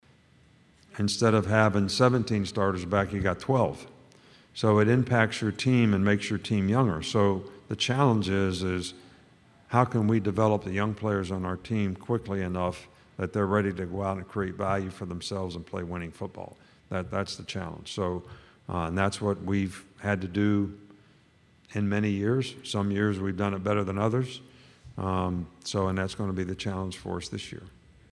During his time on the podium, Saban talked quarterback disputes, player development, staff changes and additions and more on what’s to come this season.